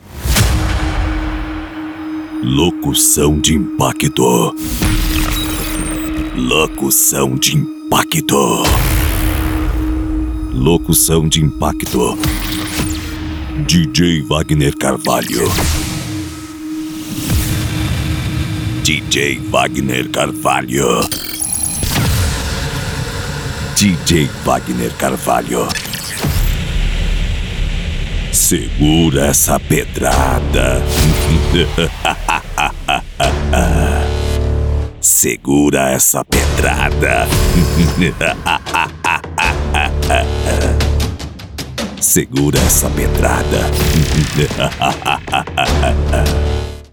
Demo Impacto:
Spot Comercial